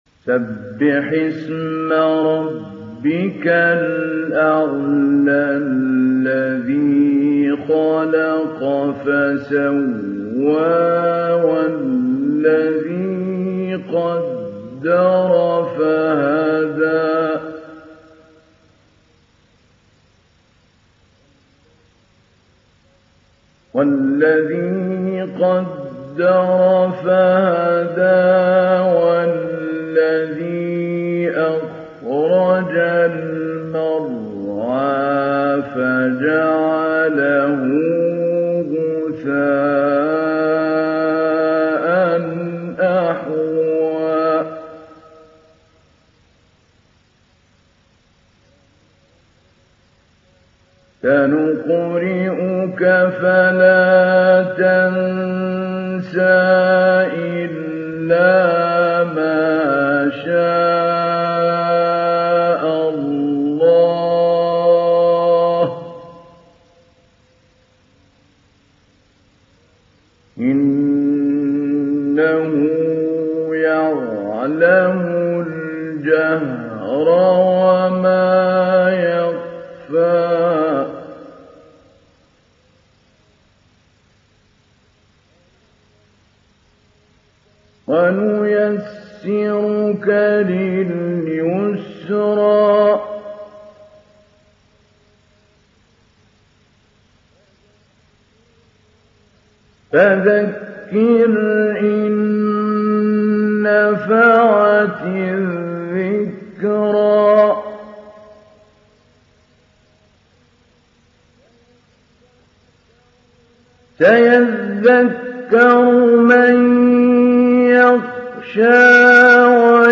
تحميل سورة الأعلى mp3 محمود علي البنا مجود (رواية حفص)
تحميل سورة الأعلى محمود علي البنا مجود